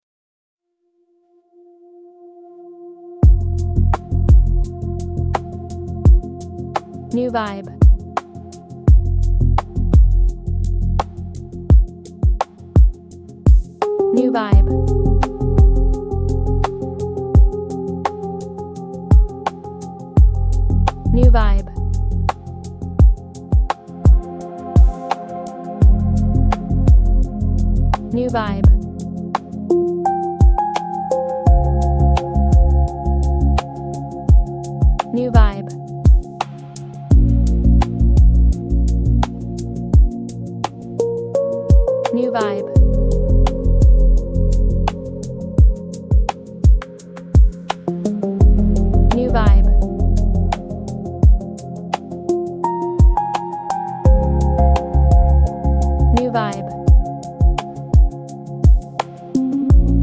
Genre: Easy Listening